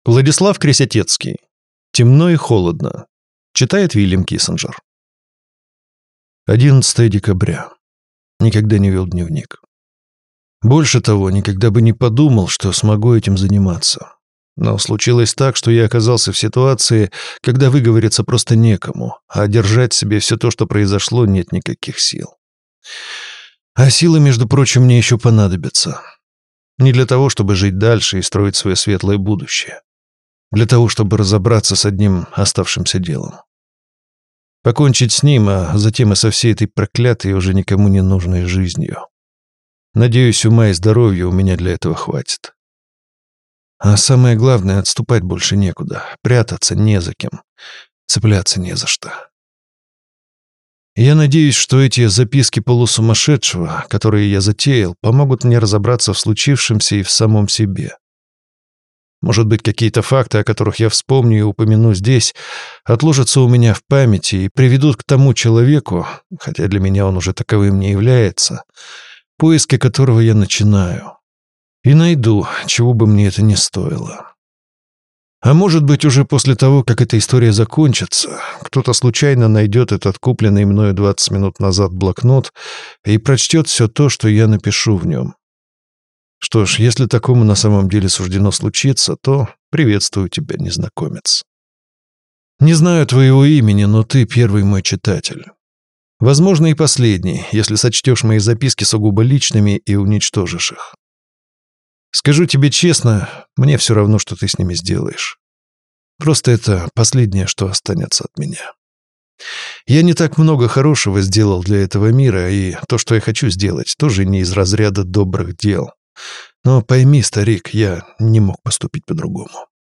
Аудиокнига Темно и холодно | Библиотека аудиокниг